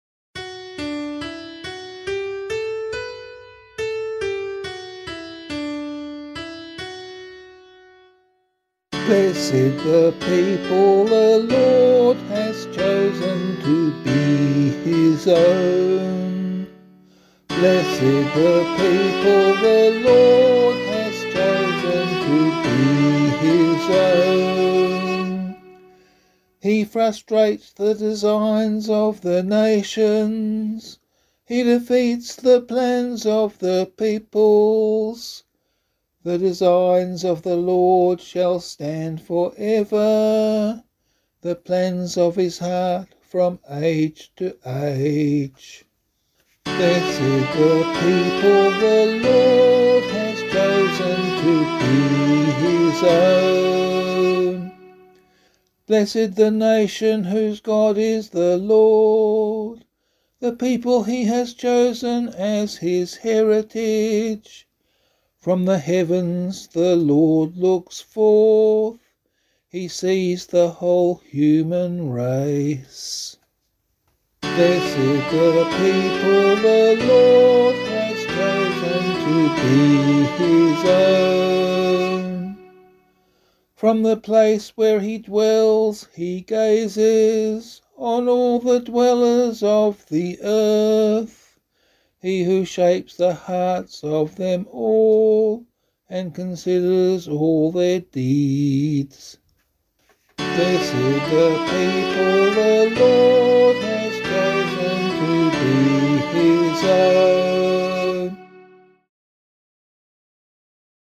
031 Pentecost Vigil Psalm 1 [Abbey - LiturgyShare + Meinrad 5] - vocal.mp3